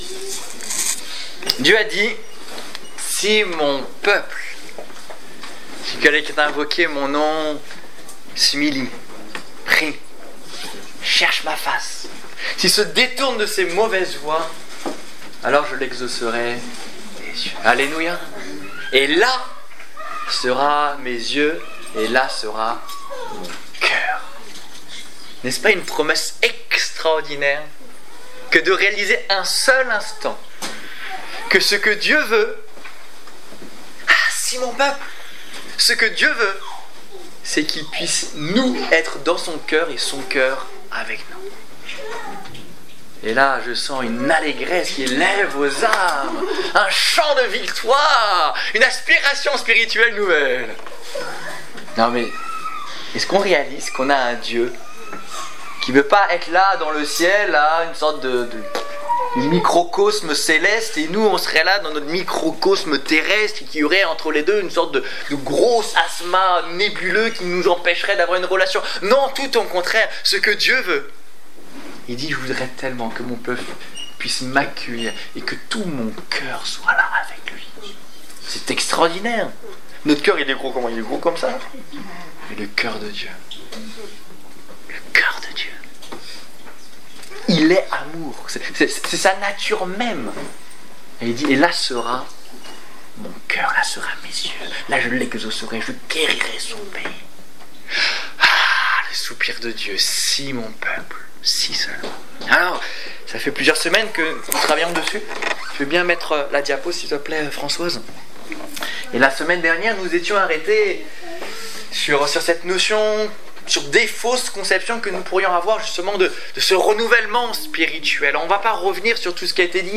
Culte du 14 décembre 2014 Ecoutez l'enregistrement de ce message à l'aide du lecteur